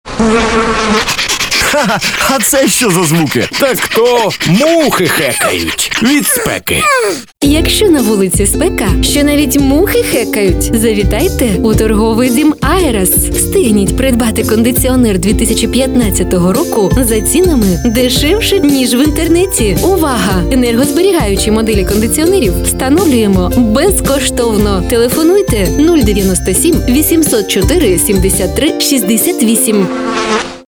Игровой аудиоролик (украинский язык)
Игровой аудиоролик кондиционеры